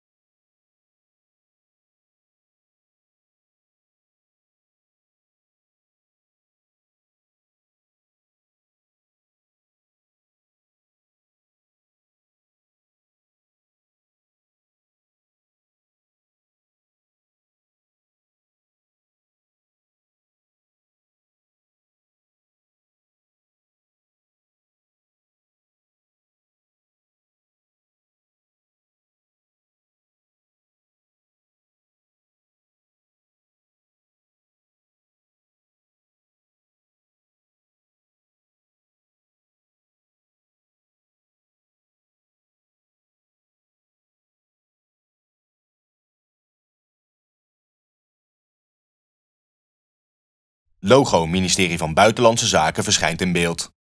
*Japanse hiphopbeat speelt*
*Japanse hiphopbeat eindigt*